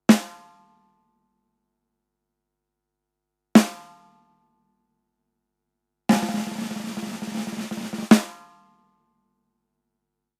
Próbki dzwiękowe Audio Technica AE-3000
Audio Technica AE3000 mikrofon - werbel perkusyjny